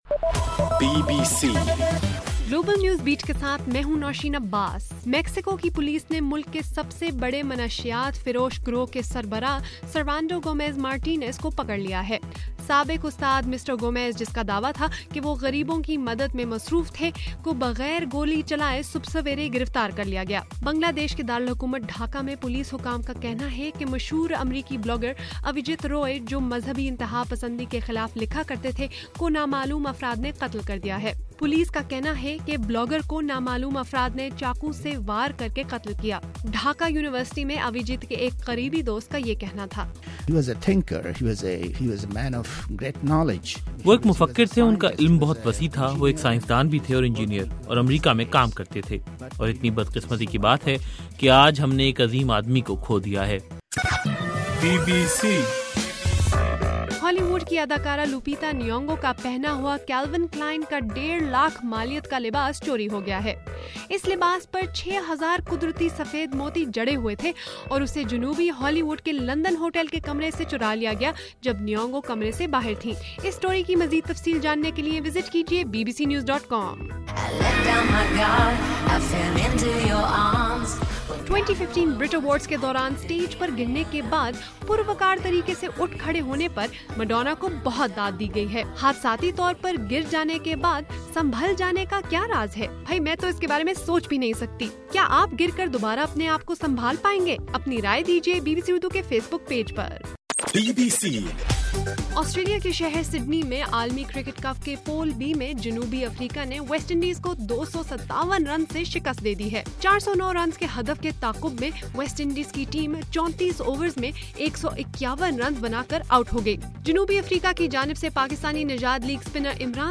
فروری 27: رات 9 بجے کا گلوبل نیوز بیٹ بُلیٹن